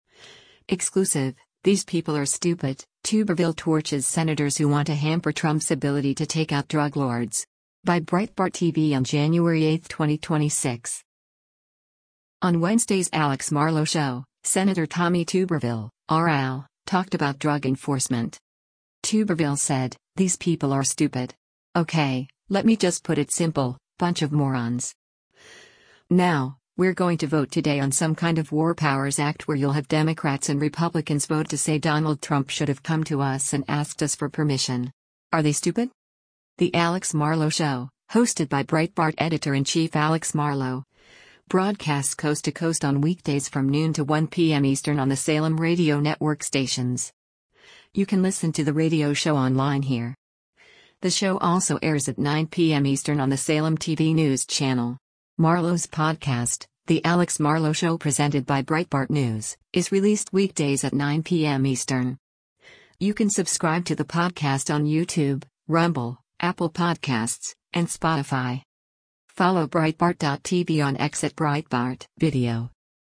On Wednesday’s “Alex Marlow Show,” Sen. Tommy Tuberville (R-AL) talked about drug enforcement.
The Alex Marlow Show, hosted by Breitbart Editor-in-Chief Alex Marlow, broadcasts coast to coast on weekdays from noon to 1 p.m. Eastern on the Salem Radio Network stations.